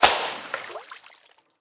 SFX_globoImpacta.wav